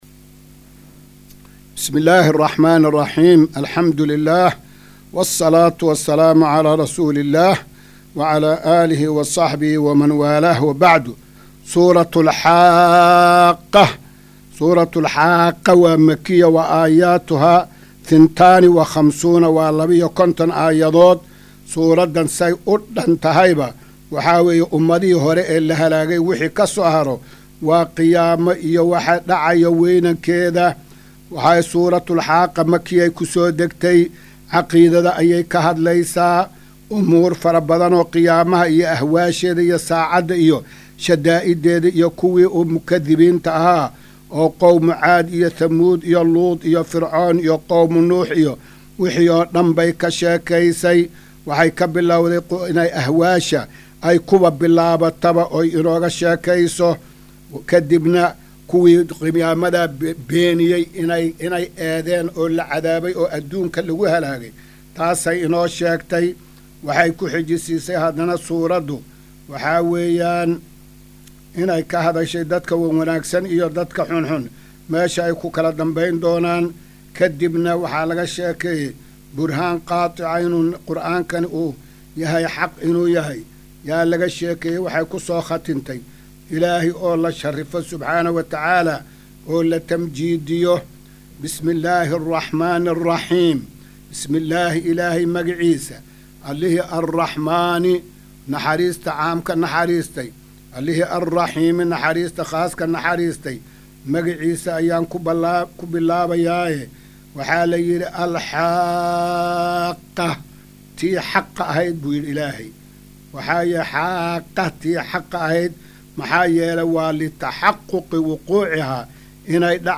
Maqal:- Casharka Tafsiirka Qur’aanka Idaacadda Himilo “Darsiga 271aad”